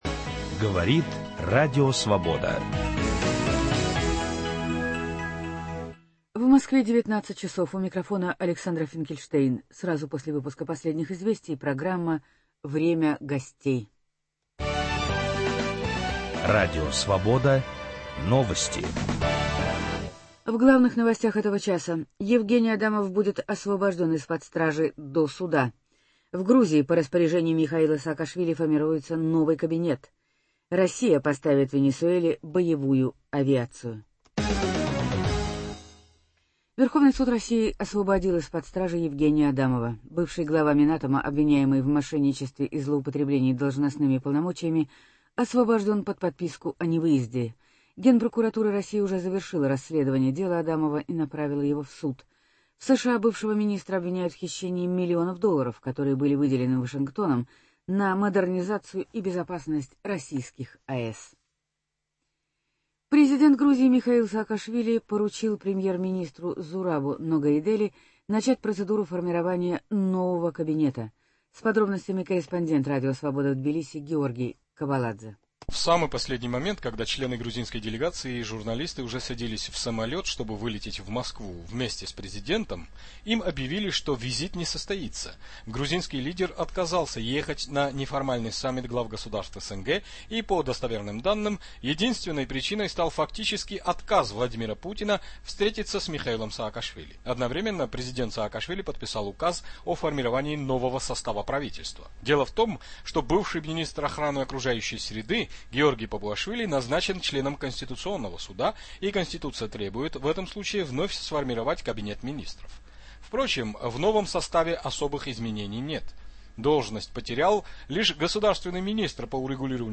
Планы новой украинской коалиции. В студии - бывший министр Кабинета министров Украины, один из известных украинских экспертов по политическим проблемам Виктор Иванович Лисицкий.